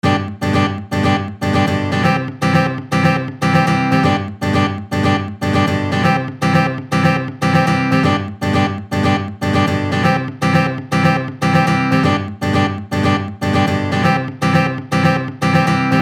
slide open chords on guitar
Example 1 - Strum